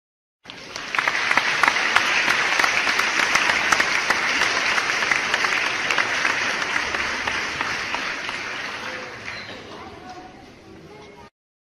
Aplausos-Efecto-De-Sonido.mp3
MJDvTPnftxC_Aplausos-Efecto-De-Sonido.mp3